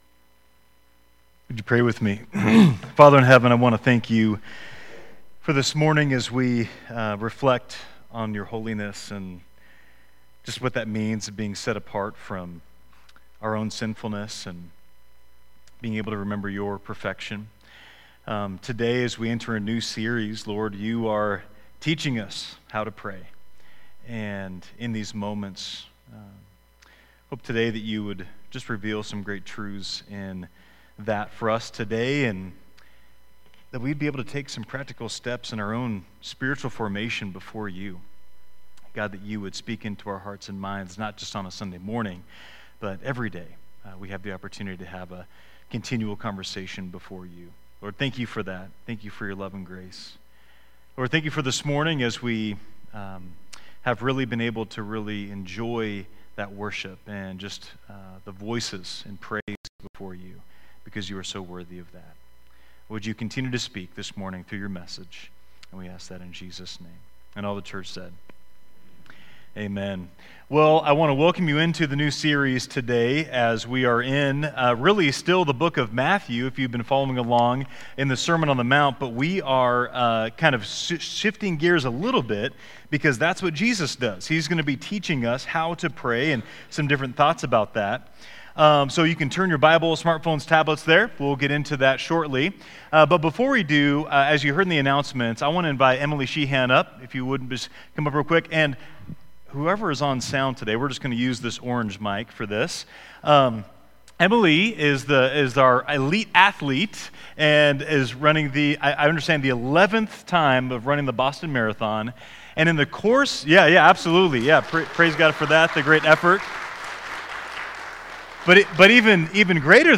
Sermons | Enterprise Christian Church